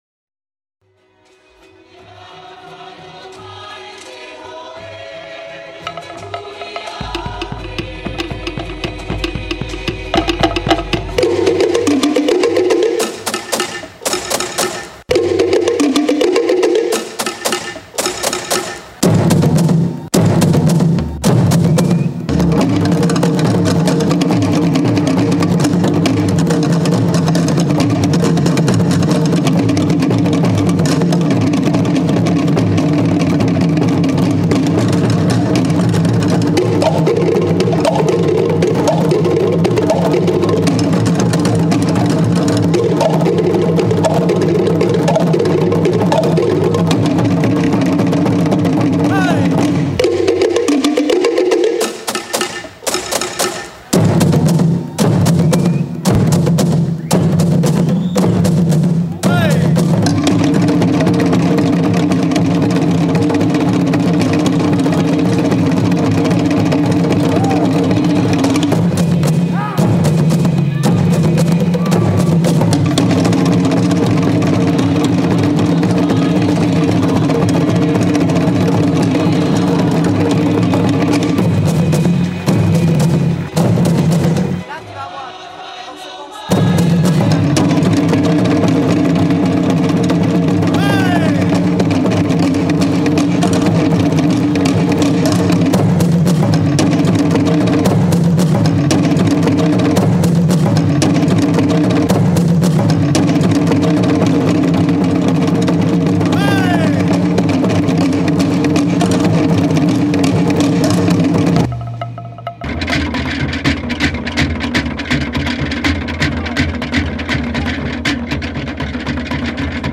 ethnique - percussions - iles - danse - tahiti